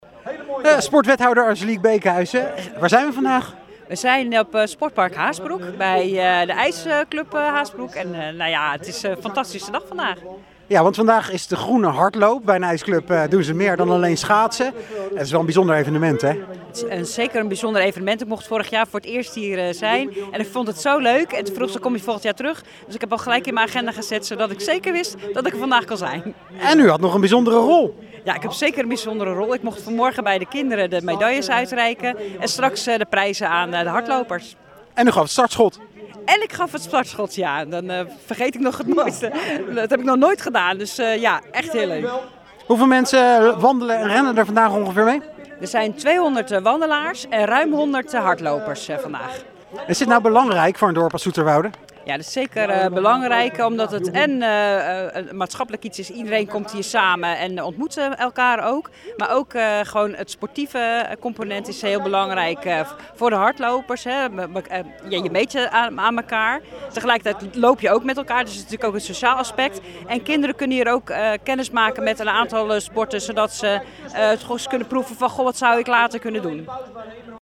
Interview Maatschappij Sport Zoeterwoude